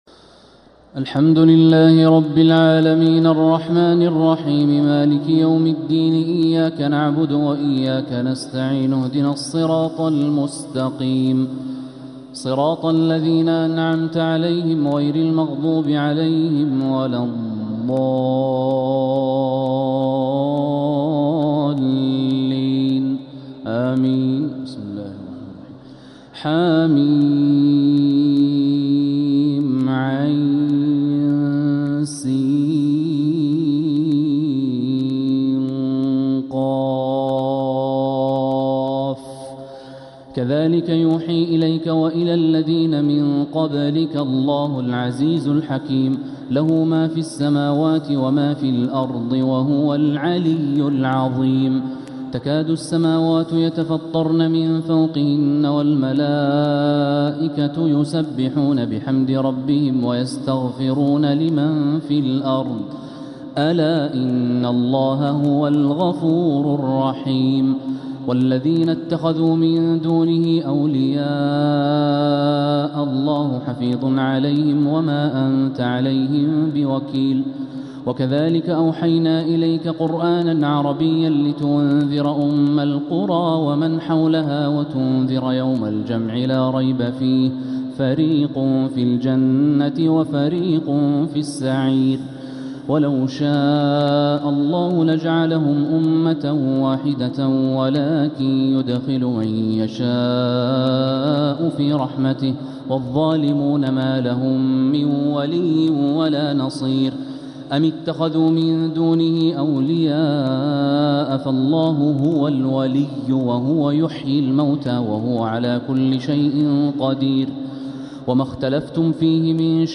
تراويح ليلة 26 رمضان 1446هـ سورة الشورى كاملة و الزخرف (1-45) | taraweeh 26th night Ramadan 1446H Surah Ash-Shuraa and Az-Zukhruf > تراويح الحرم المكي عام 1446 🕋 > التراويح - تلاوات الحرمين